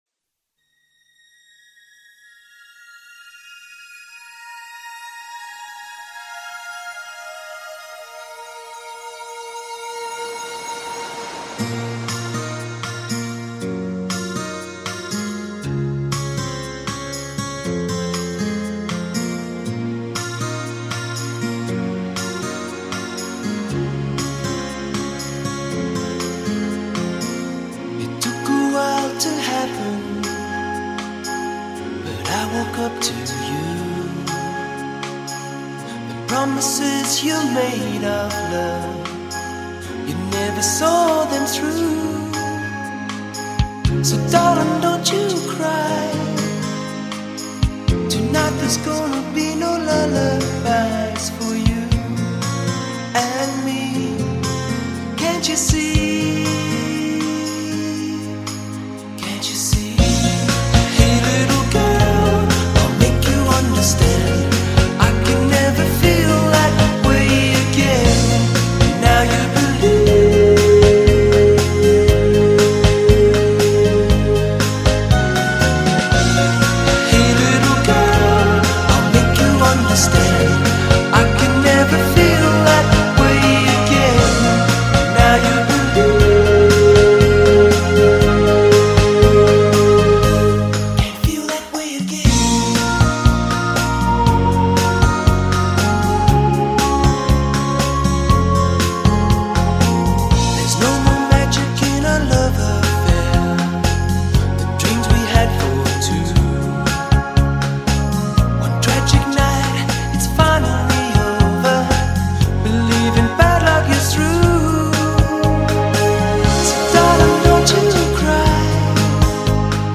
Эту балладу, к стыду своему, только вчера услышал, хотя она 1990-го года По-моему, классно)